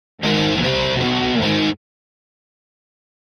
Guitar Heavy Metal Finale Chords -Short